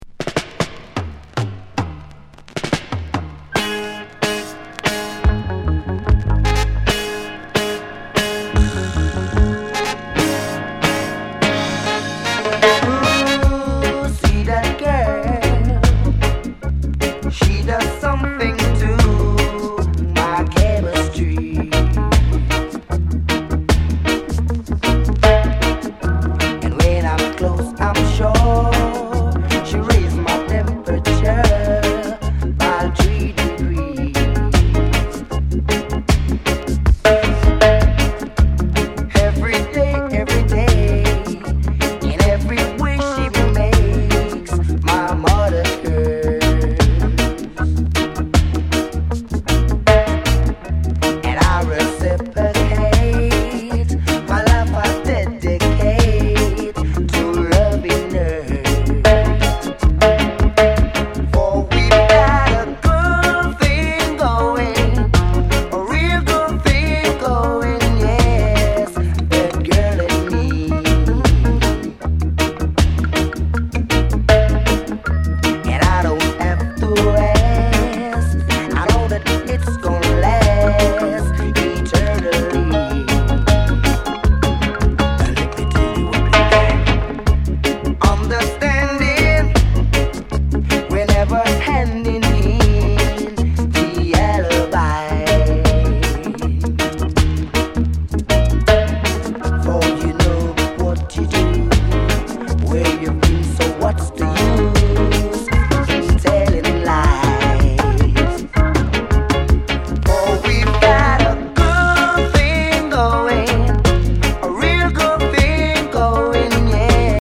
スウィートな中にも熱さが光る素晴らしい内容です。